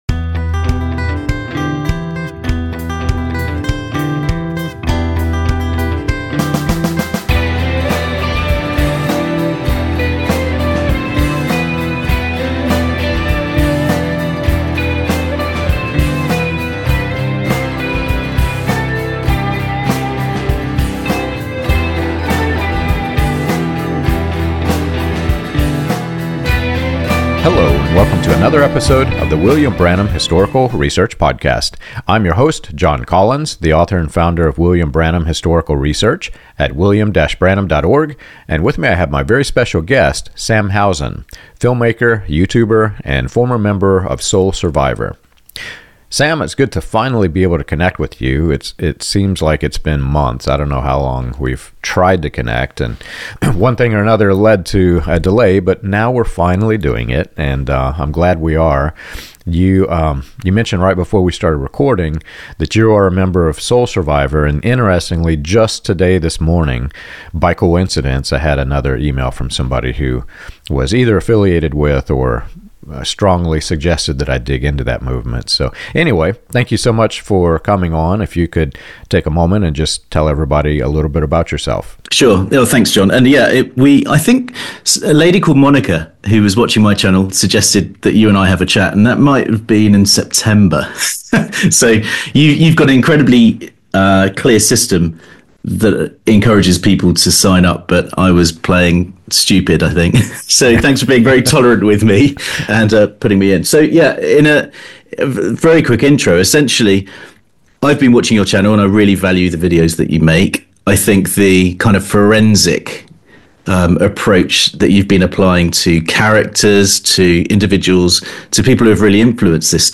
This conversation examines indoctrination during adolescence, the emotional and psychological aftereffects of high-control religious systems, and practical ways to re-engage faith without fear, manipulation, or celebrity leadership.